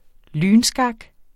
Udtale [ ˈlyːn- ]